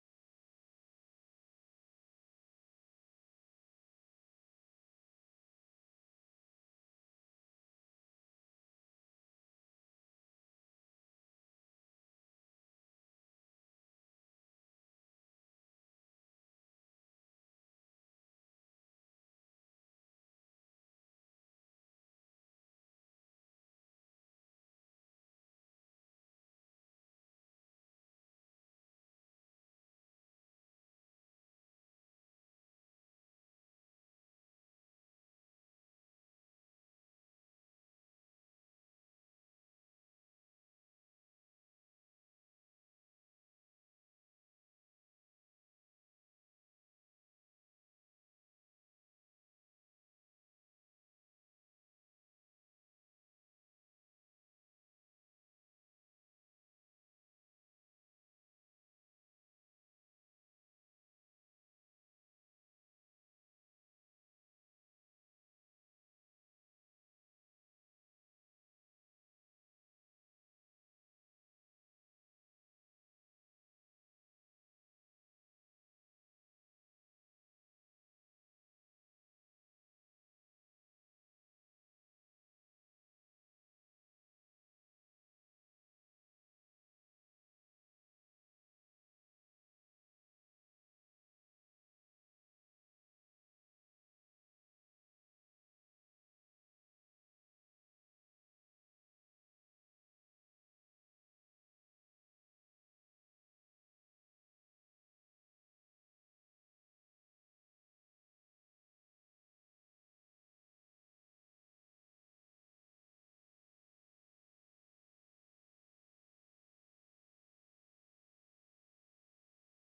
19ª Sessão Ordinária de 2020